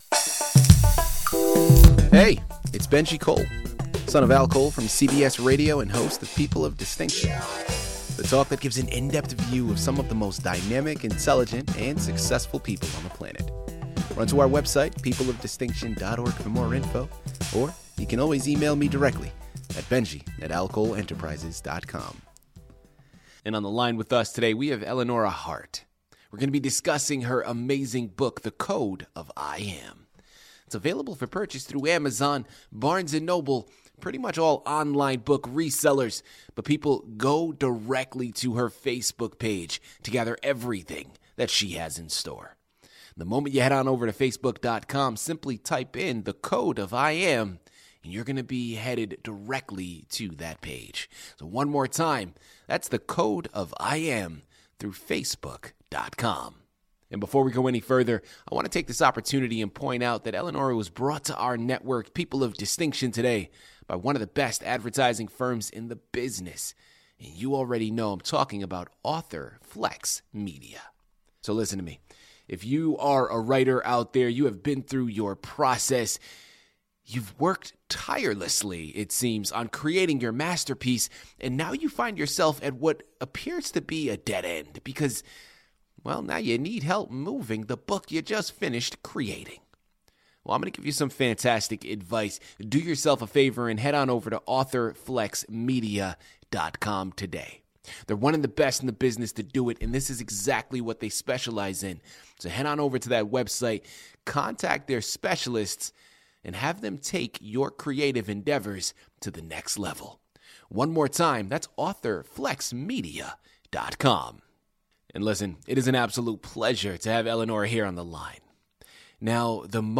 Listen to her audio CBS Radio Interview.